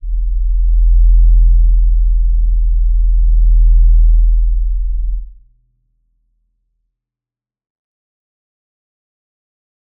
G_Crystal-E1-pp.wav